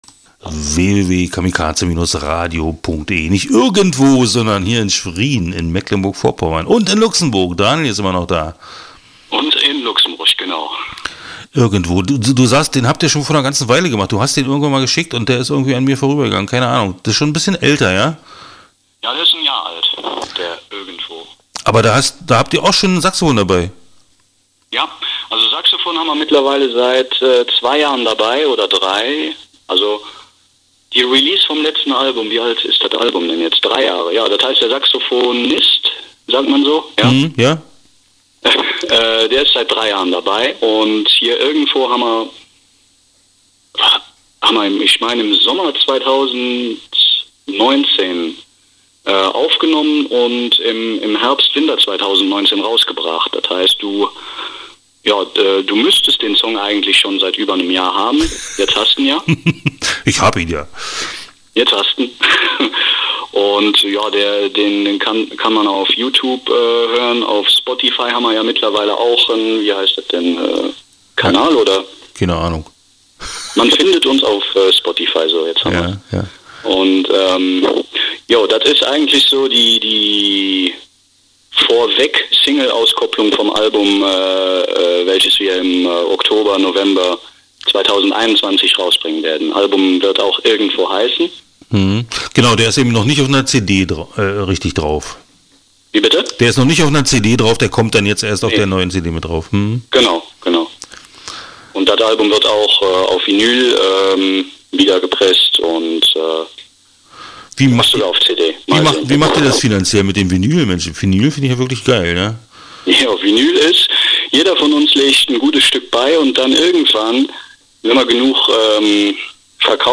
2021-04-19.Interview Fehl-Tritt 1 (9:12)